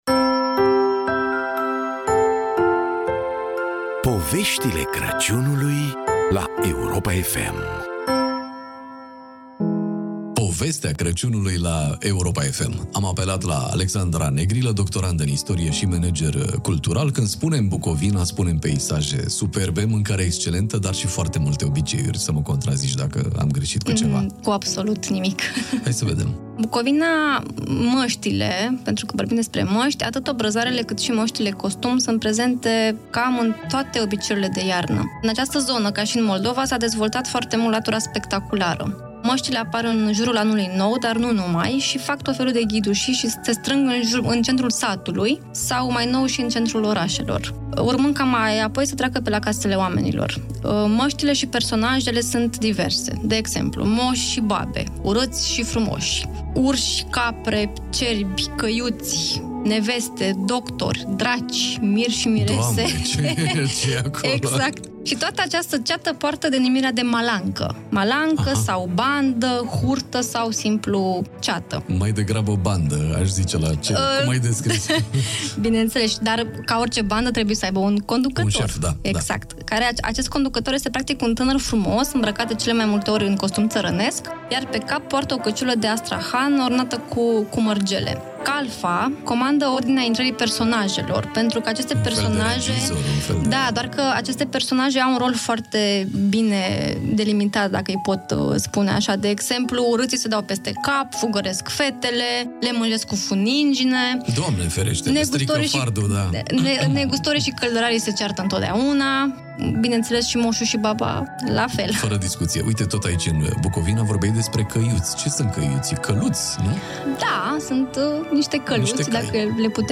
Interviul integral